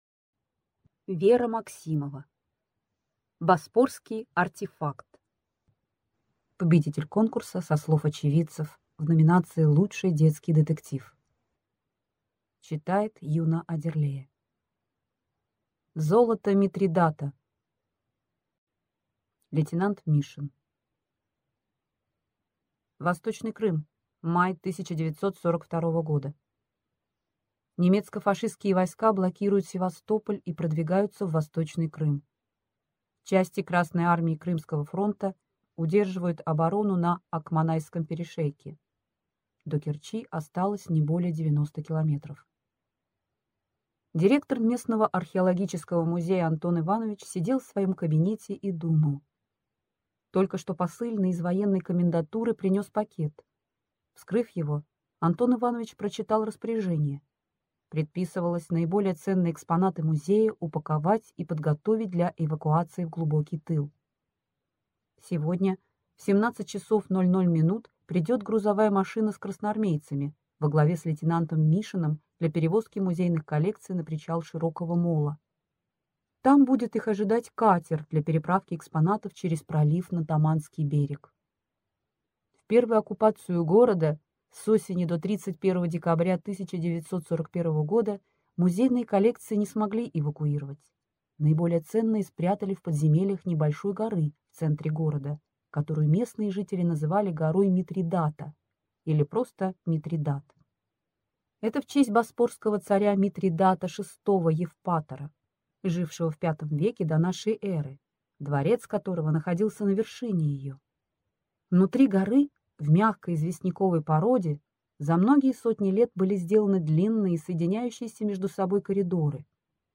Аудиокнига Боспорский артефакт | Библиотека аудиокниг